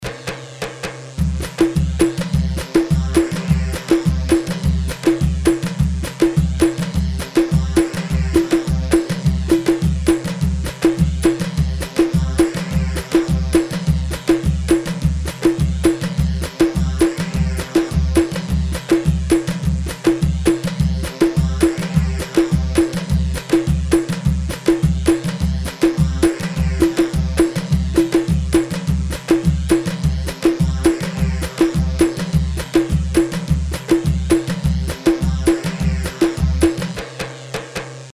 Khaleeji – Darbakati
Shahooh 3/4 156